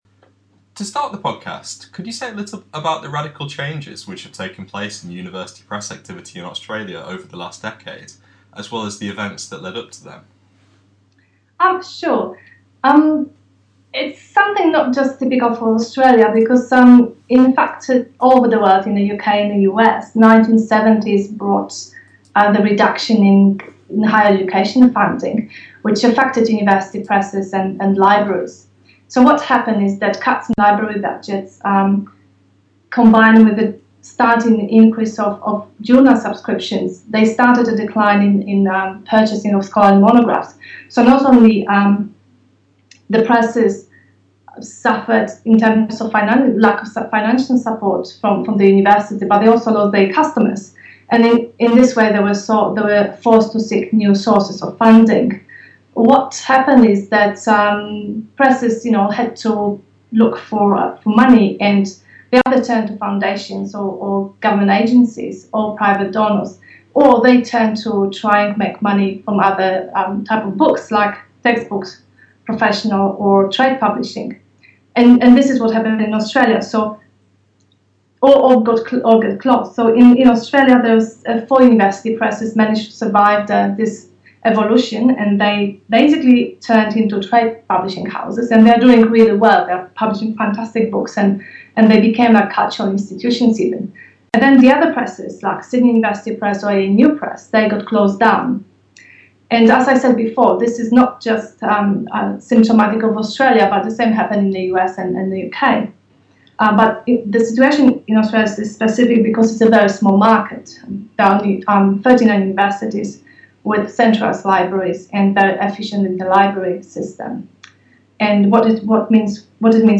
Scholarly Publishing and ePresses – Interview